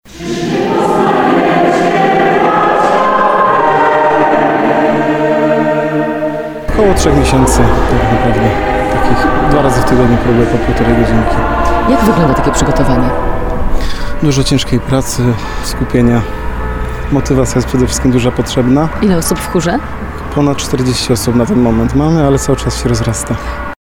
28dyrygent.mp3